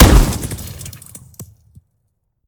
weap_romeo870_fire_plr_01_db.ogg